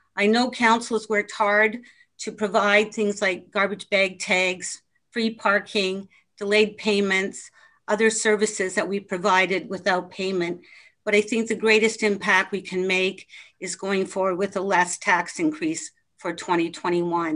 One topic dominated the conversation when Quinte West city council reviewed the proposed 2021 operations budget at its meeting Monday.
Councillor Karen Sharpe was one of many who disagreed with the increase.